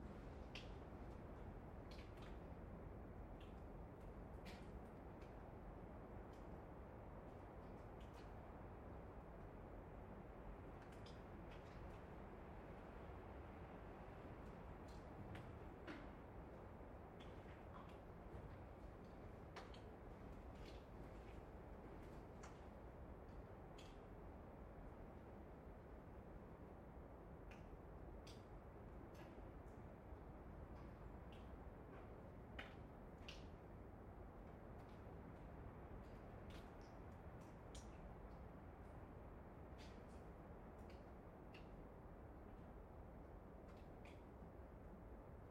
Ambient_Bunker_Water_Dripping_Wind_Stereo_Ortf_8040.ogg